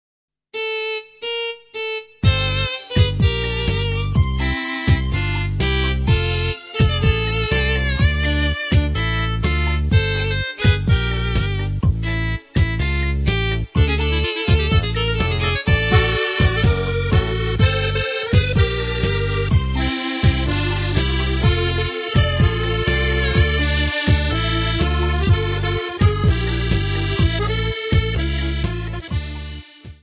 3 CD set of Polish Folk Songs.